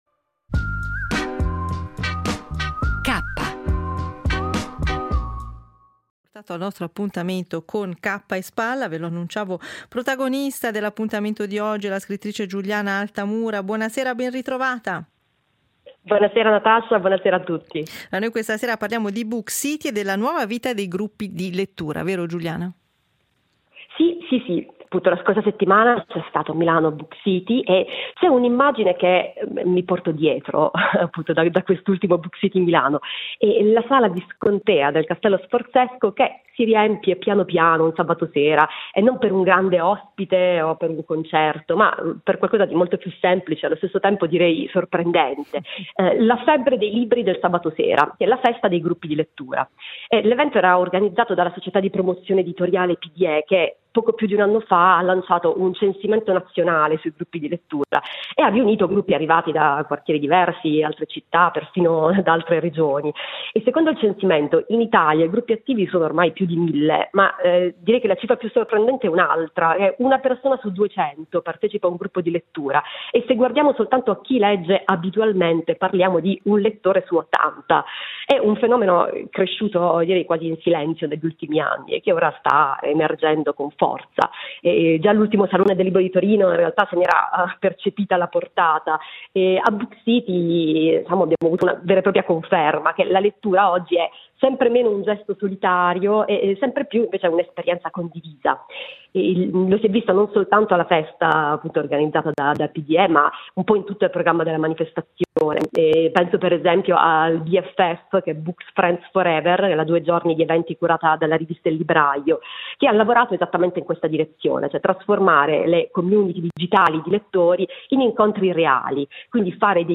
L’editoriale del giorno